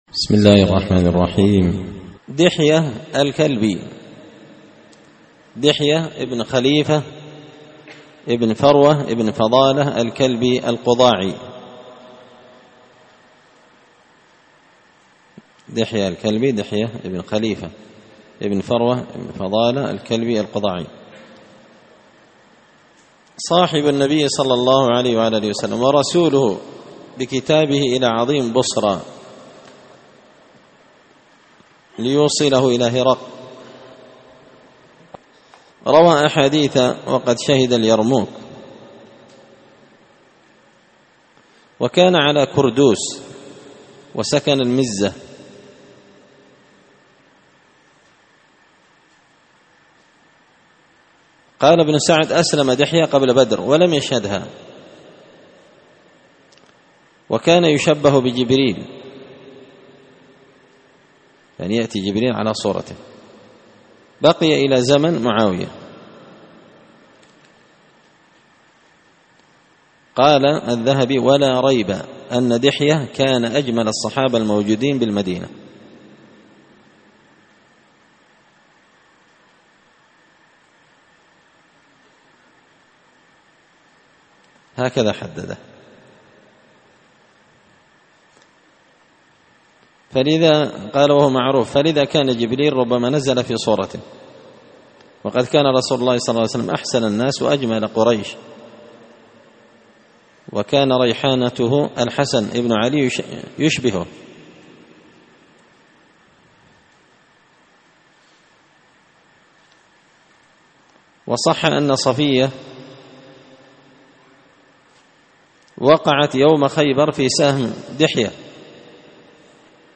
الدرس 149 دحية الكلبي – قراءة تراجم من تهذيب سير أعلام النبلاء
دار الحديث بمسجد الفرقان ـ قشن ـ المهرة ـ اليمن